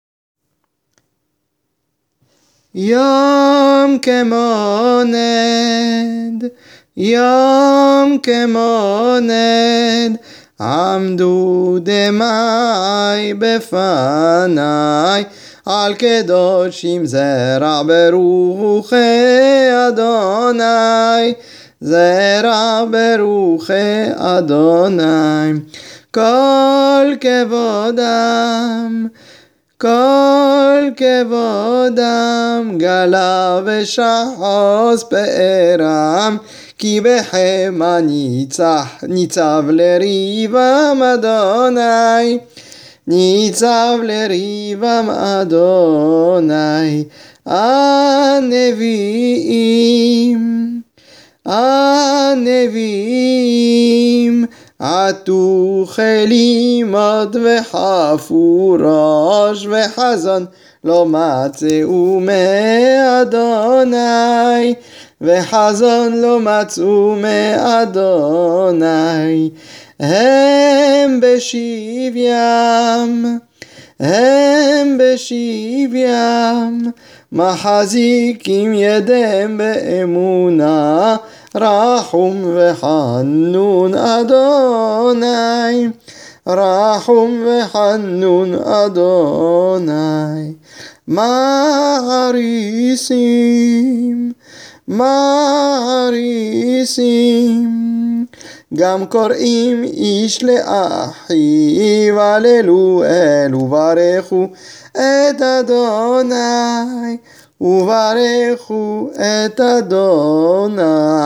Hazanout